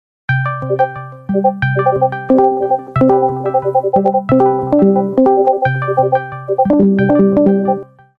Discord Spaming Notification Bouton sonore